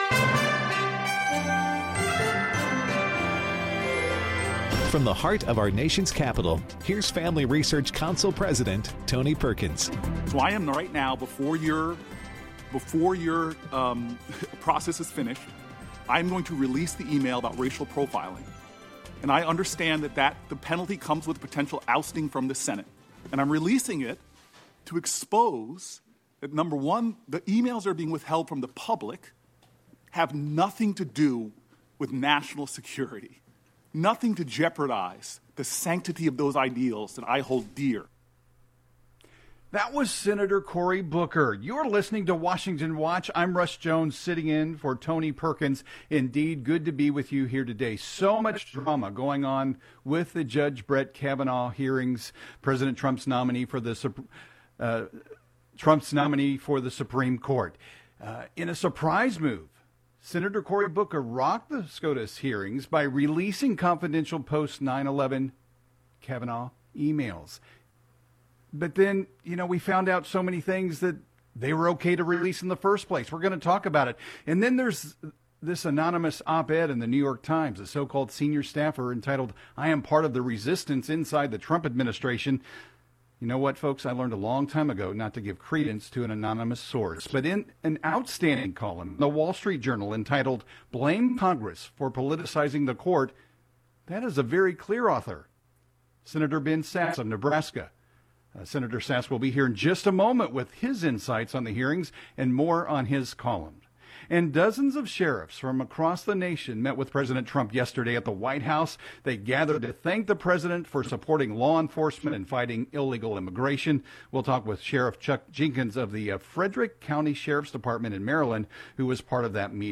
Senate Judiciary Committee member Sen. Ben Sasse (R-Neb.) joins our guest host with the latest news from the confirmation hearings for judge Brett Kavanaugh to the U.S. Supreme Court. Frederick County (Md.) Sheriff Chuck Jenkins is here to highlight President Trump's meeting yesterday with many of the country's sheriffs.